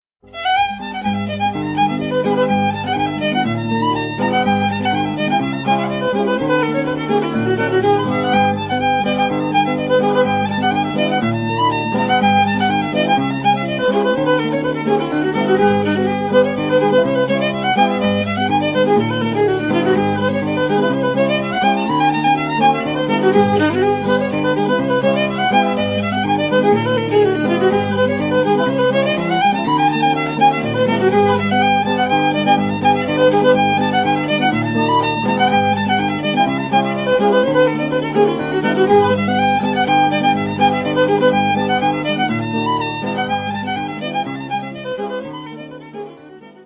guitar playing